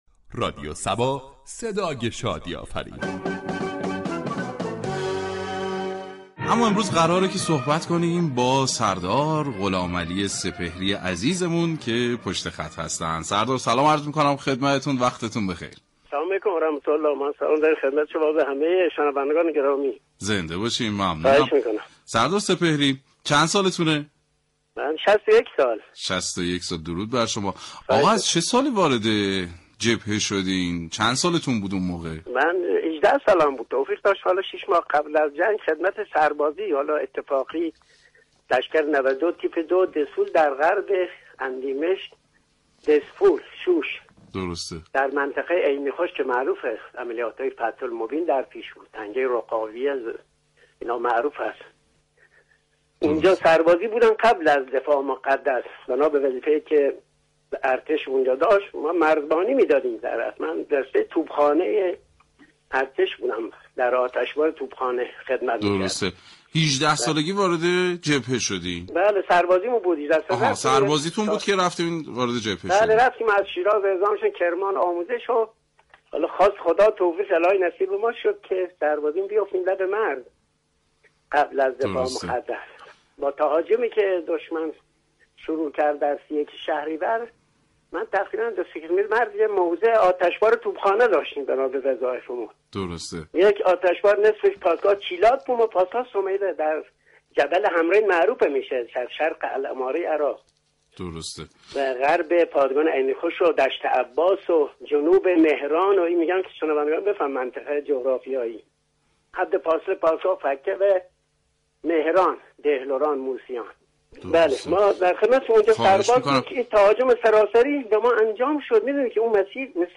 "همرزم" ویژه برنامه ای است ،با محوریت گفتگو با یكی از جانبازان یا ایثارگرانی كه فرصت حضور در جبهه‌های جنگ را داشته اند.